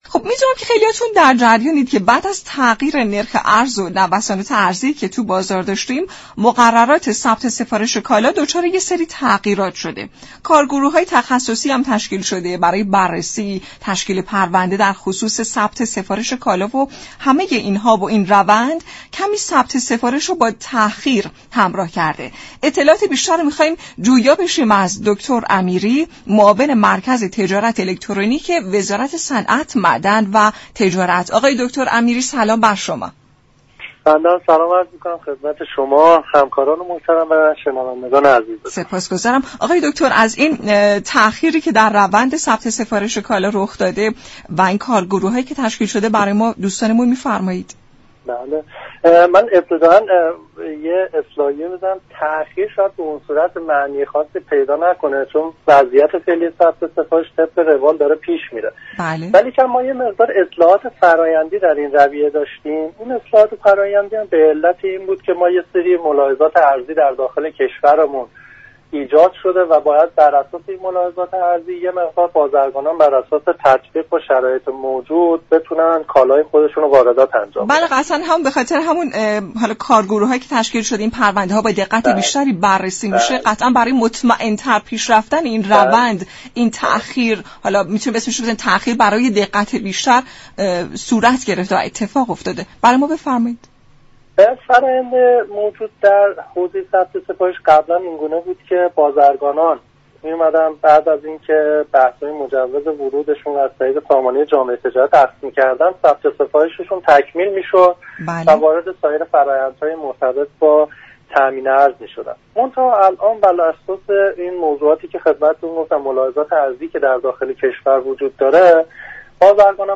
معاون تجارت الكترونیك وزارت صنعت، معدن و تجارت در گفت و گو با برنامه «سلام ایران» گفت